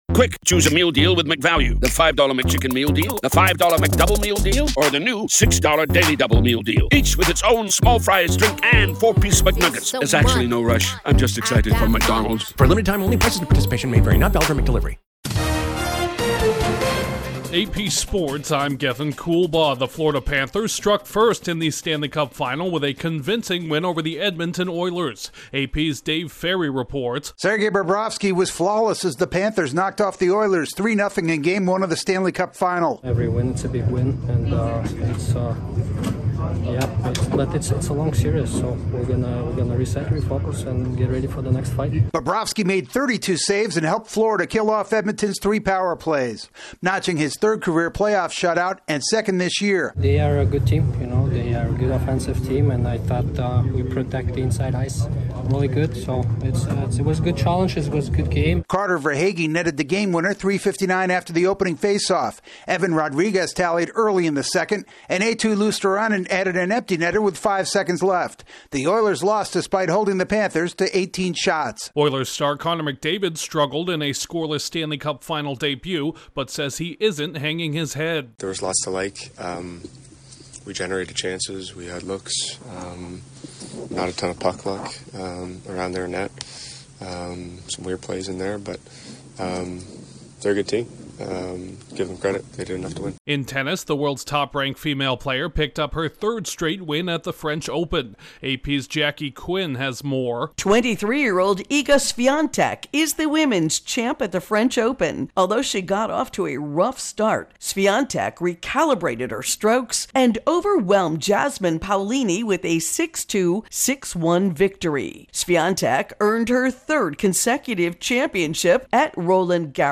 The Panthers blank the Oilers, Iga Swiatek prevails again at the French Open, the Dodgers pound the Yankees, the Phillies rout the Mets in London and Caitlin Clark is snubbed for the Paris Olympics. Correspondent